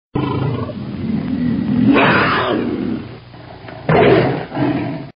Kostenlose Klingeltöne Tiger Voice